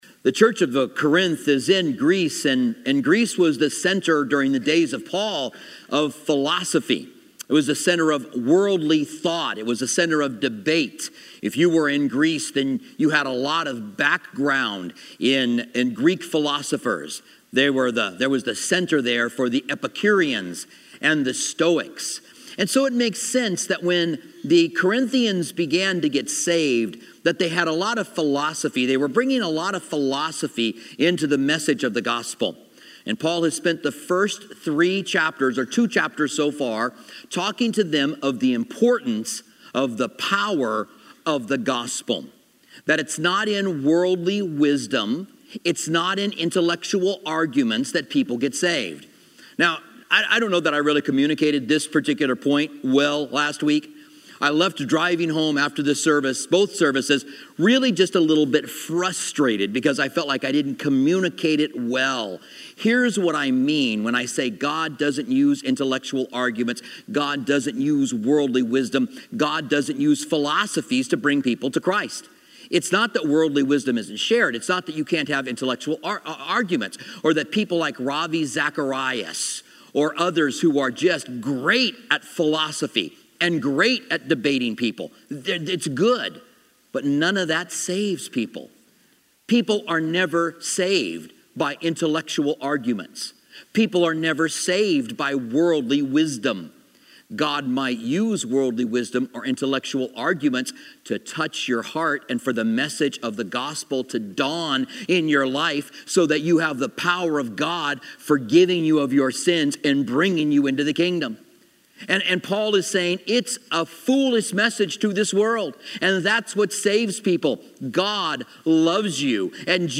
Commentary on 1 Corinthians